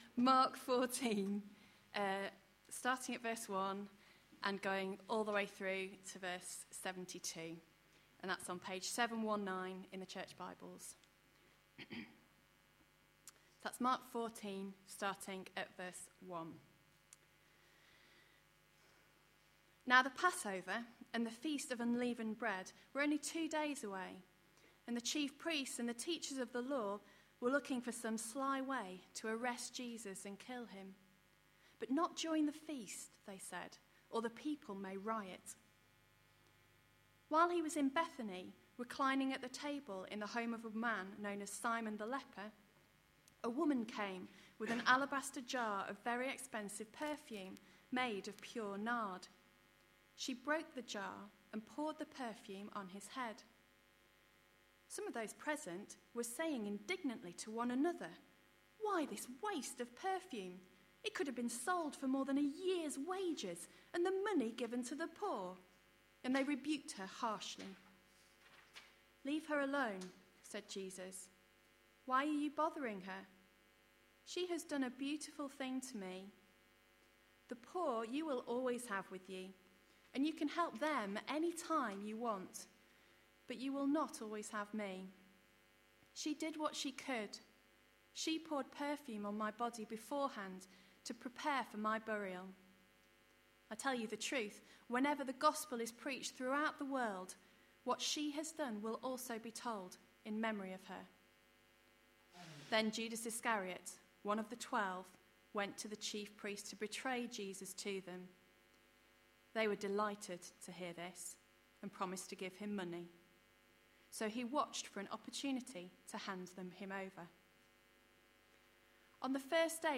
A sermon preached on 25th March, 2012, as part of our Mark series.